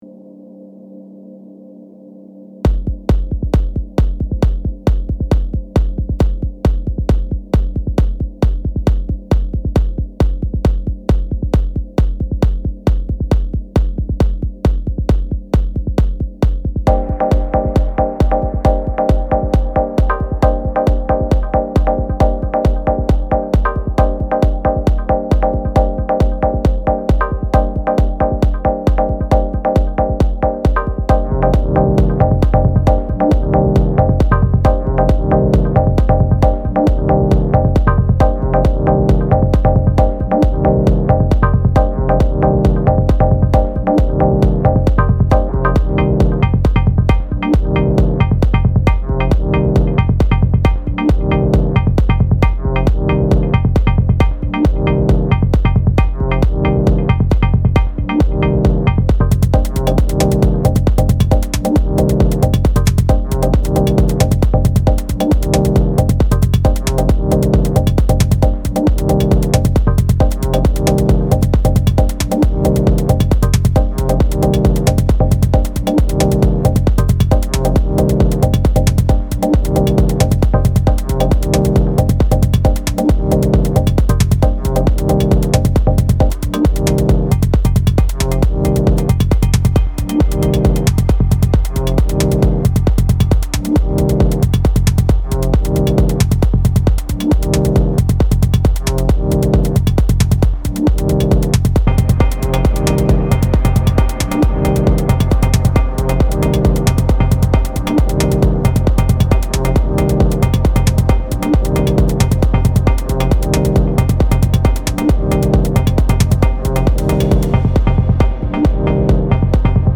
4 track Digitakt jam